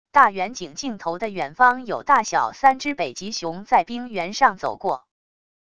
大远景镜头的远方有大小三只北极熊在冰原上走过wav音频